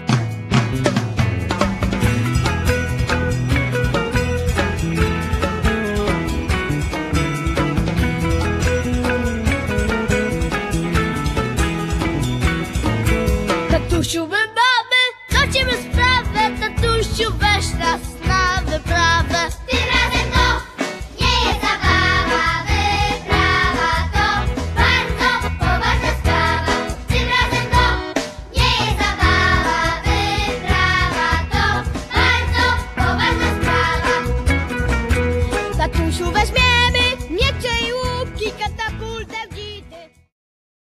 dwudziestoosobowe, muzykujące, szalone przedszkole!
perkusja
akordeon
kobza ośmiostrunowa
dzieciaki - śpiew